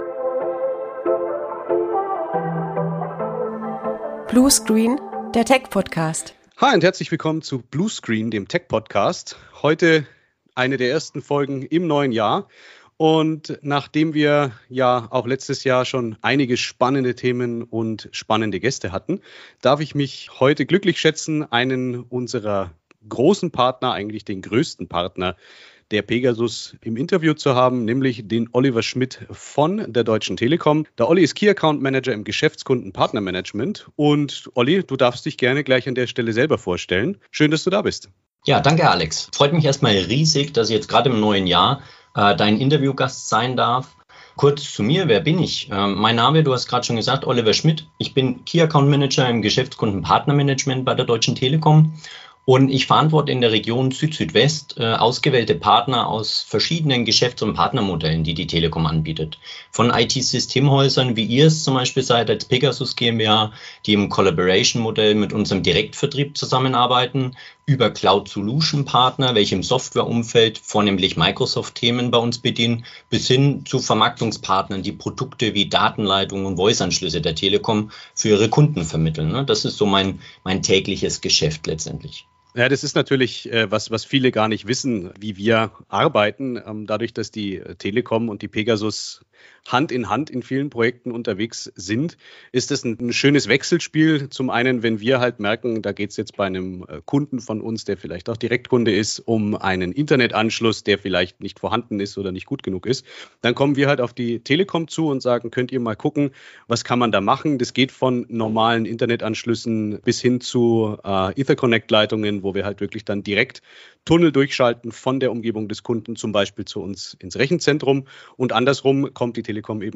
Folge 7 von Bluescreen - Der Tech-Podcast! Im Interview